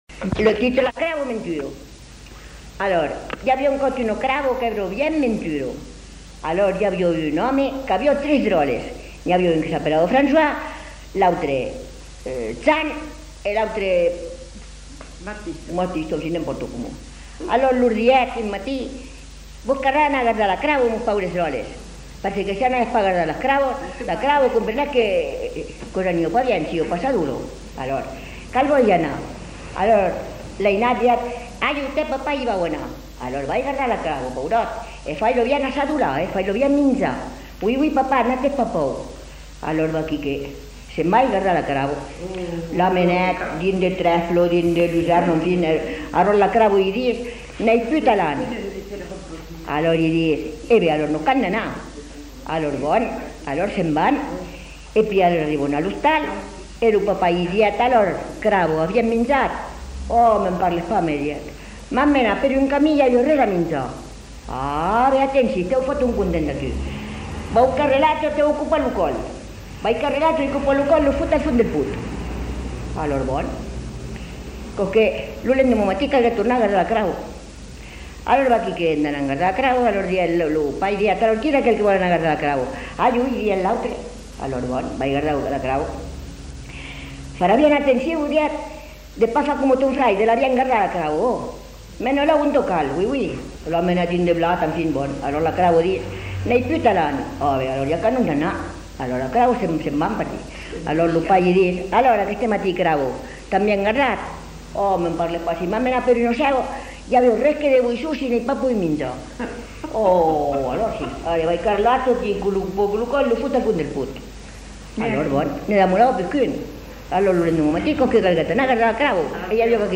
Aire culturelle : Haut-Agenais
Lieu : Cancon
Genre : conte-légende-récit
Type de voix : voix de femme
Production du son : parlé